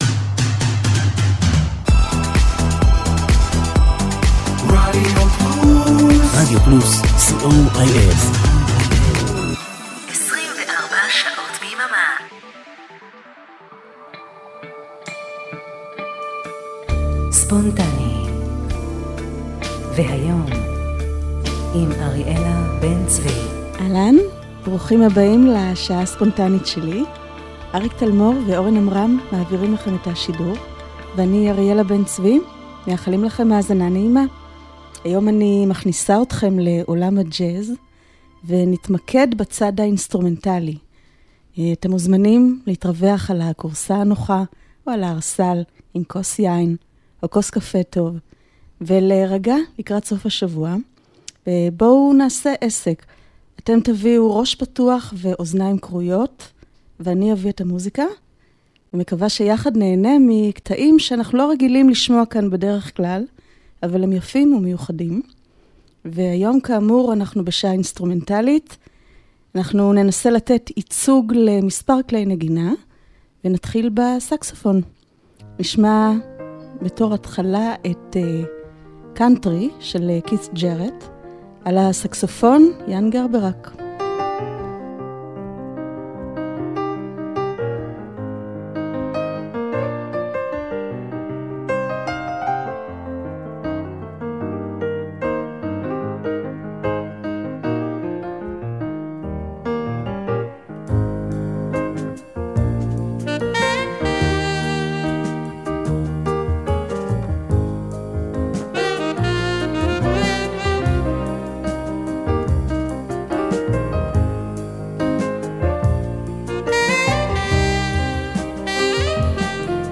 השעה הזו תוקדש לג׳אז אינסטרומנטלי.